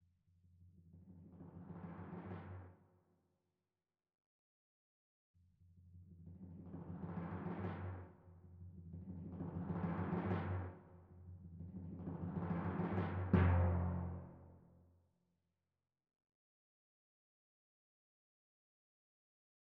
So, once I switched to Miroslav Philharmonik for the Timpani, the first thing I noticed is that it does a crescendo automatically, hence I removed the crescendo . . .
Additionally, I removed the Notion 3 Reverb, since the Miroslav Philharmonik VSTi instruments are played and recorded in a concert hall, hence already have natural reverberation . . .
This is the resulting WAVE file (approximately 3.5MB), where the Timpani is from Miroslav Philharmonik and there is no Notion 3 Reverb.
[NOTE: I used the default panning (full panning), and the TImpani mostly is heard in the right channel, since the Miroslav Philharmonik instruments are played and recorded in their standard locations within an orchestra, which for Timpani is stage-left or audience-right . . . ]